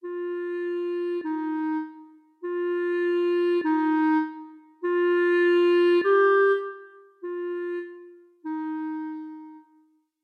Whole Steps - Clarinet Sight Reading Exercise | SightReadingMastery